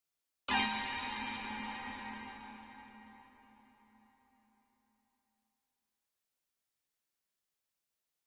String Pluck Chord Strike With Reverb 3